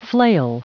Prononciation du mot flail en anglais (fichier audio)
Prononciation du mot : flail